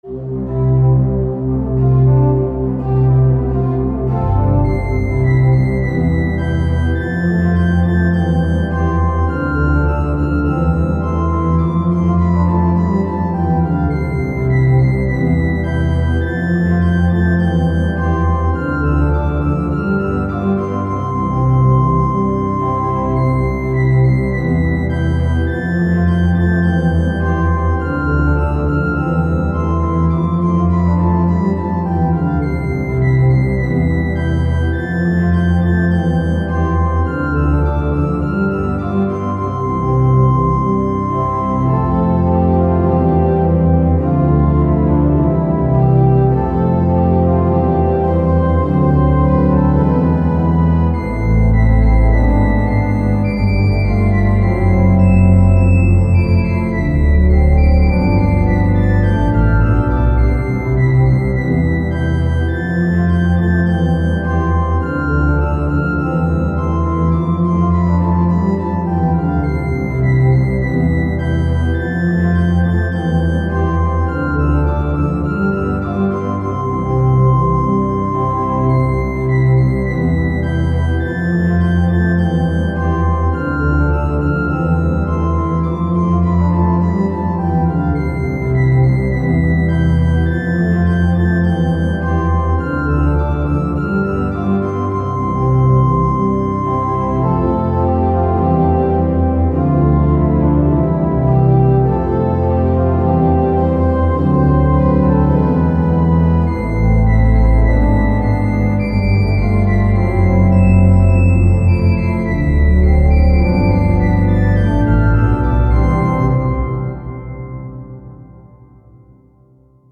pipe_organ